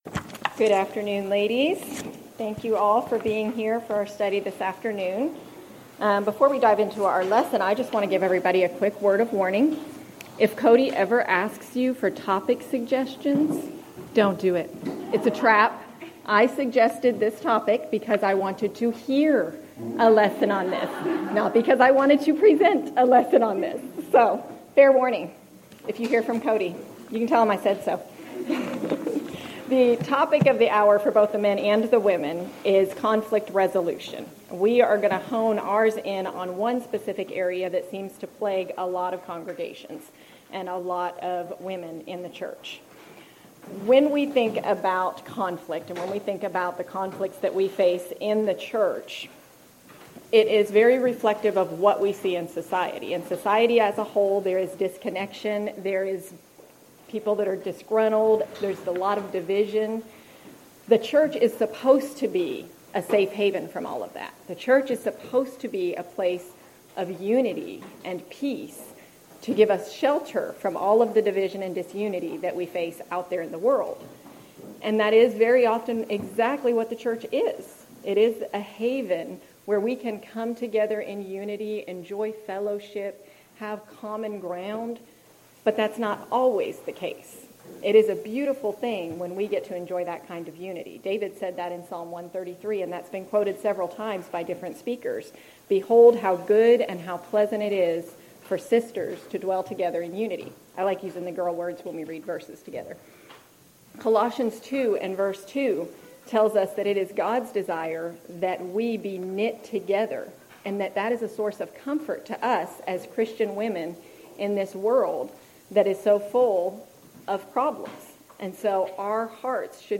Event: 4th Annual Arise Workshop
lecture